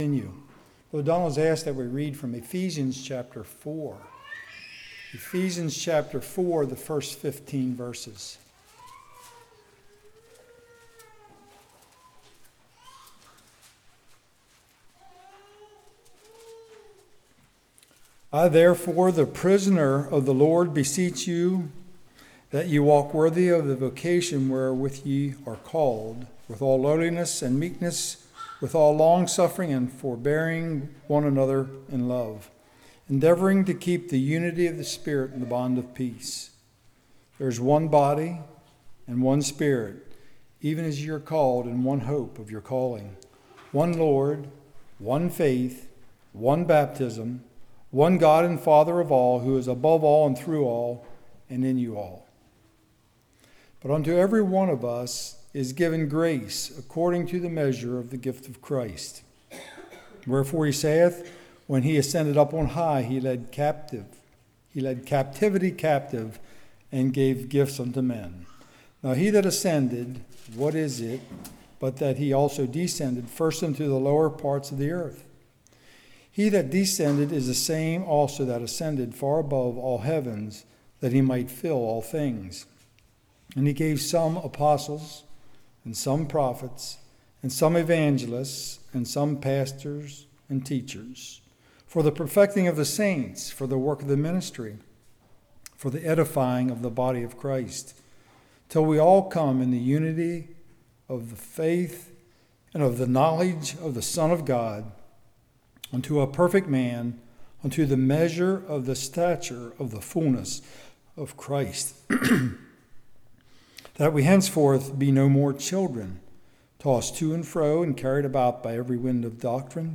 Series: Spring Lovefeast 2019
Service Type: Evening